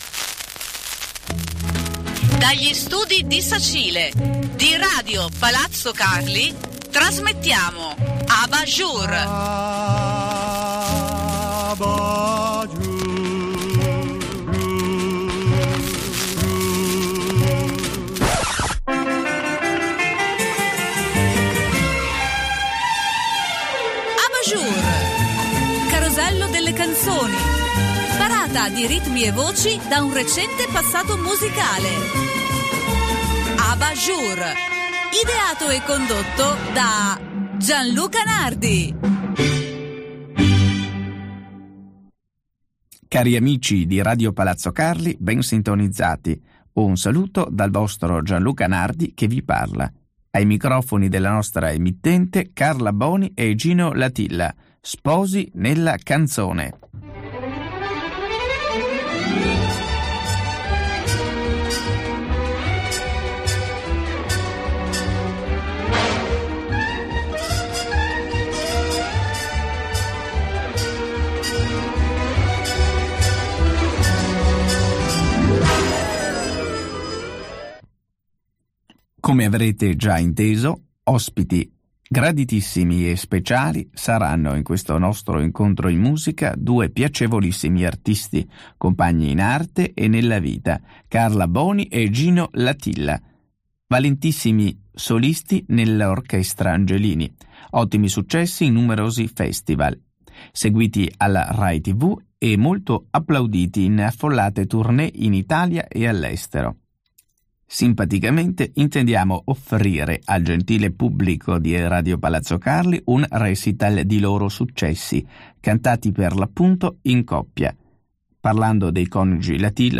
Qui, con un filo di nostalgia, vi condurranno in atmosfere passate attraverso i più celebrati artisti e le più particolari esecuzioni, prediligendo la produzione musicale fra le due guerre e giungendo al periodo del Boom economico. A ciò si aggiungano la lettura d’un buon libro e qualche curiosità irrinunciabile circa la discografia più rara e dimenticata.